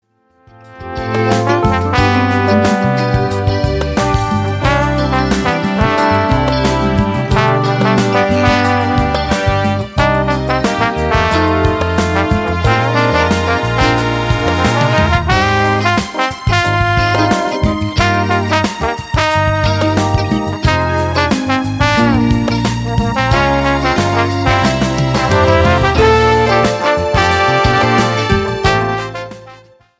POP  (03.16)